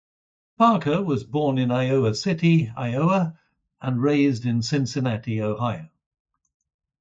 Pronounced as (IPA) /ˈpɑɹkɚ/